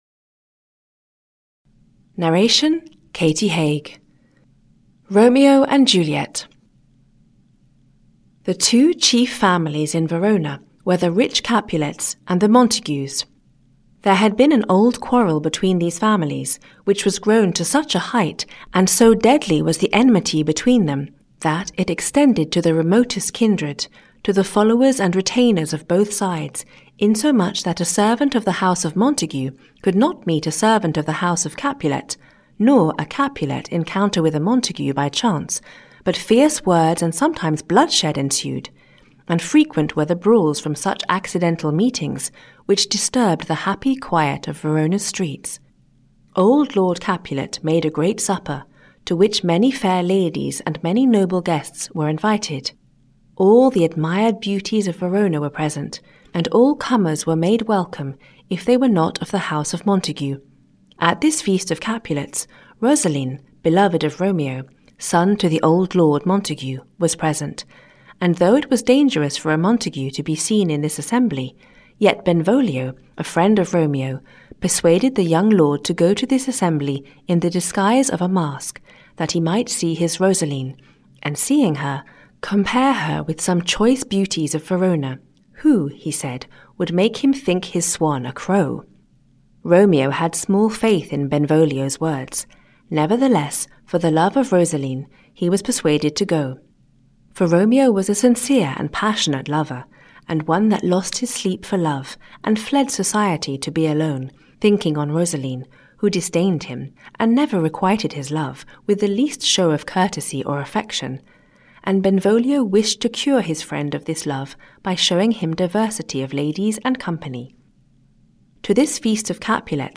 Audio knihaRomeo & Juliet by Shakespeare, a Summary of the Play (EN)
Ukázka z knihy